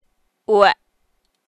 舒声韵的示范发音为阴平调，入声韵则为阴入调。
ueh.mp3